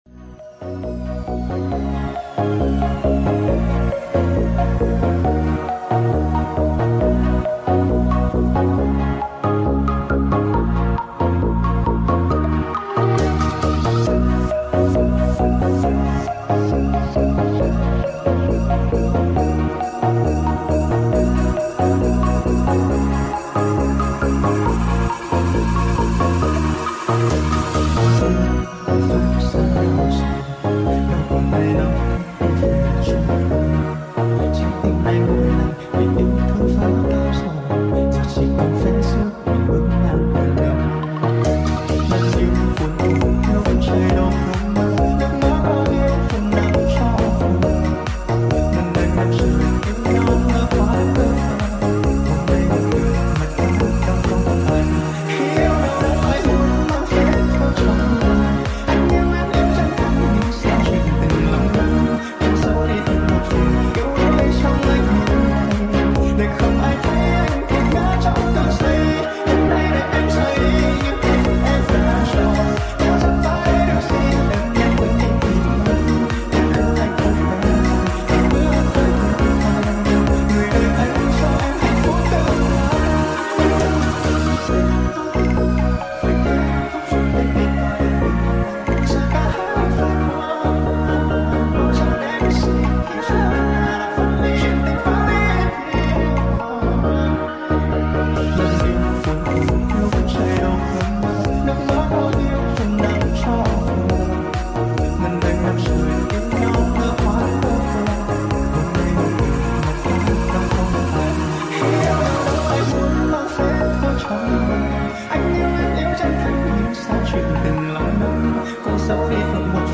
Tự động Khoá Cửa Hyundai Sound Effects Free Download